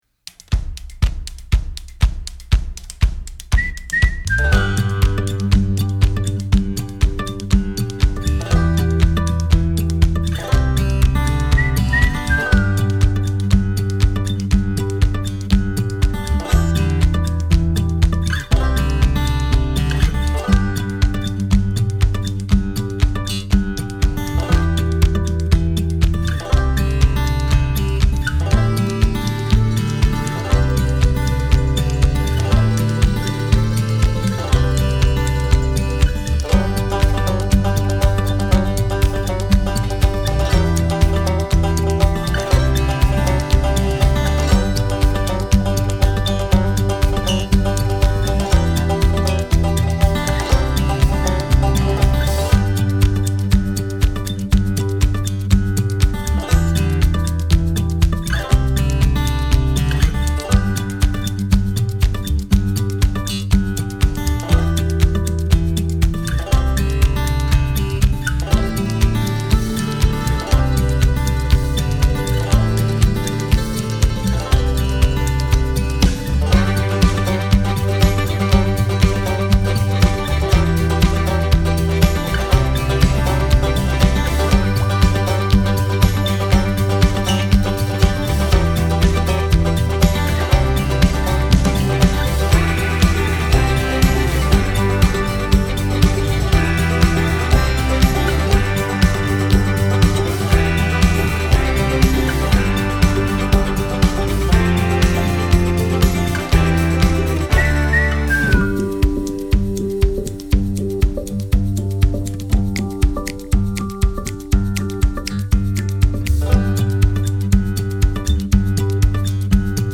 Download Instrumental